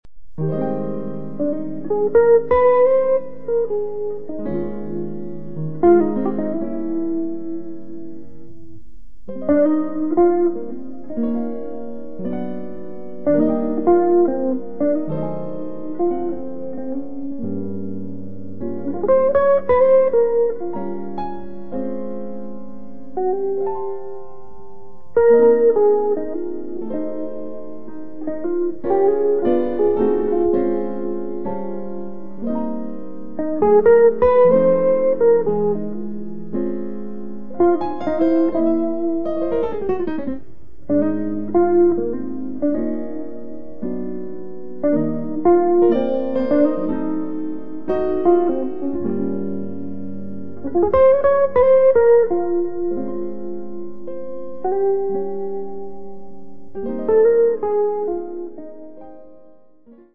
Chitarra
Hammond B3
Pianoforte
Contrabbasso
Batteria